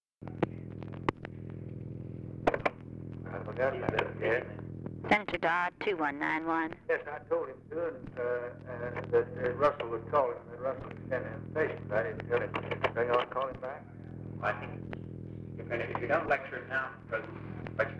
OFFICE CONVERSATION, November 29, 1963
Secret White House Tapes | Lyndon B. Johnson Presidency